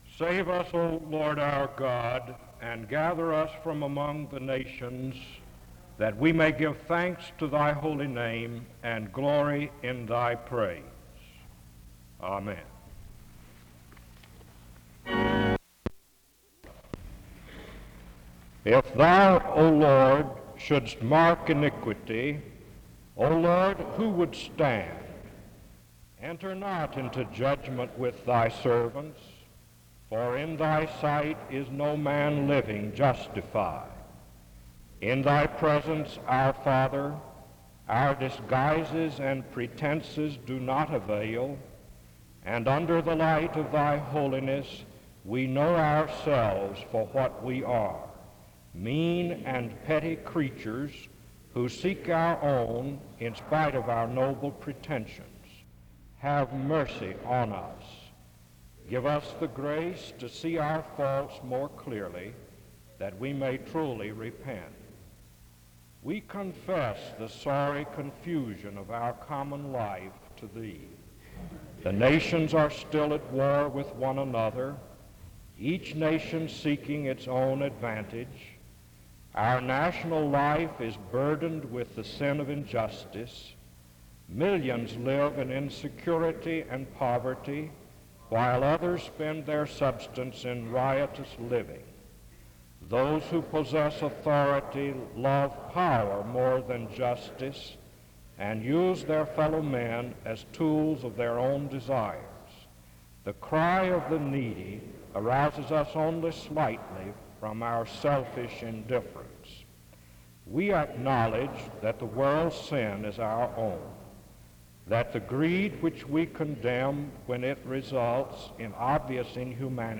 The service begins with a word of prayer from 0:00-3:28. Music plays from 4:00-5:41. Genesis 12:1-3 and Hebrews 11:8 are read from 6:19-7:35. Kitagawa speaks from 7:51-43:59. His message is titled, “Christ on the Frontier.” His message was focused on the church’s mission to reach the world for Christ. A closing prayer is offered from 44:24-45:28.